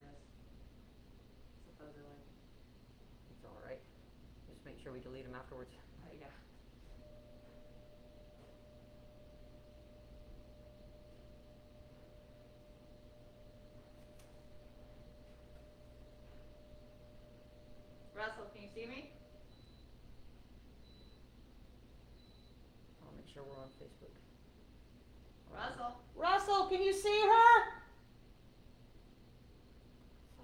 (captured from the facebook livestream)
01. soundcheck (0:30)